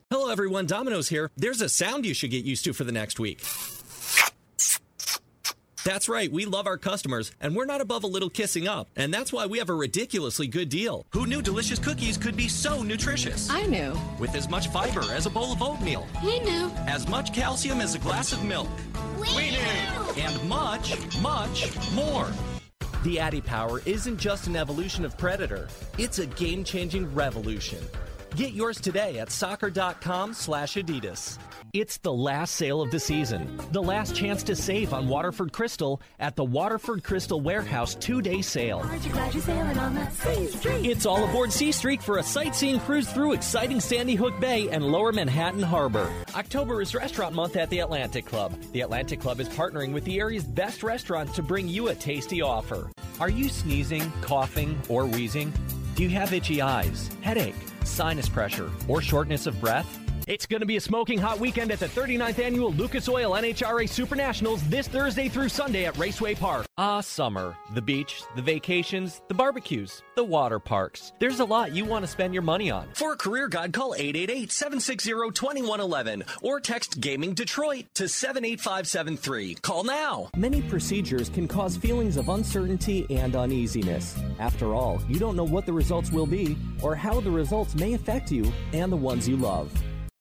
Male
English (North American)
Yng Adult (18-29), Adult (30-50)
Television & Radio Spots
Words that describe my voice are Upbeat, Conversational, Friendly.